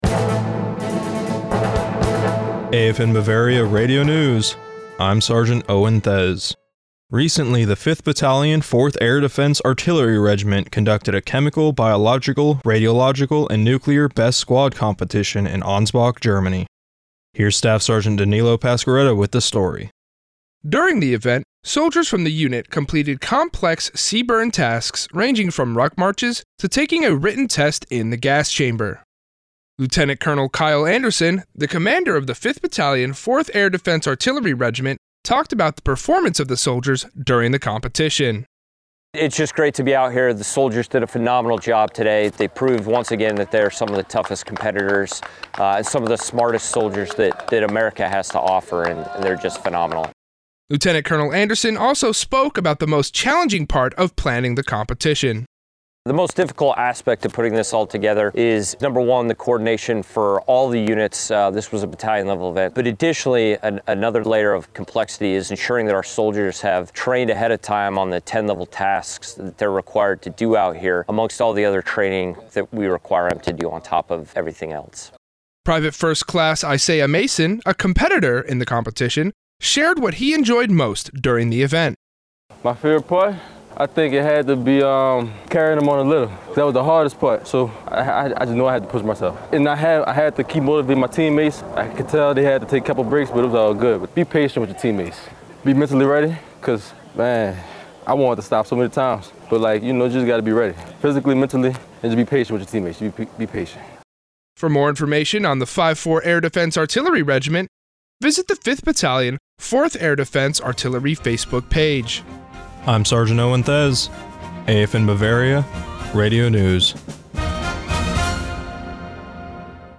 AFN Bavaria Radio News March 13, 2024